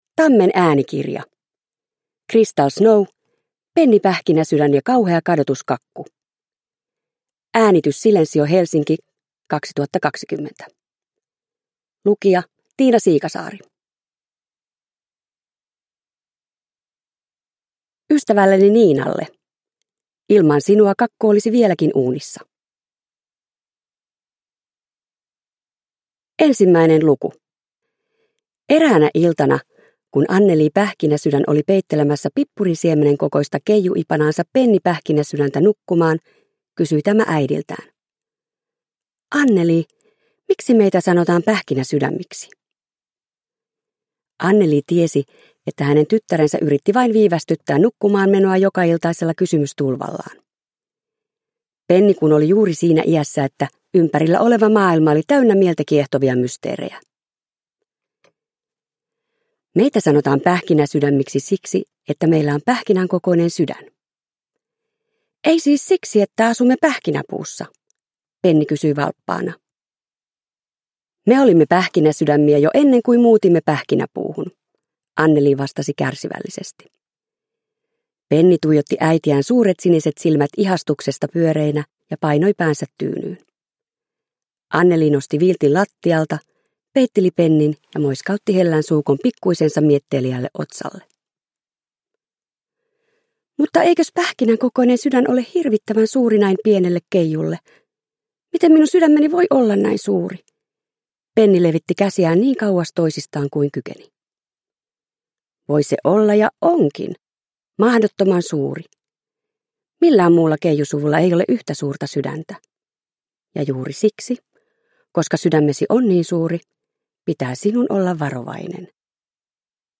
Penni Pähkinäsydän ja kauhea kadotuskakku – Ljudbok – Laddas ner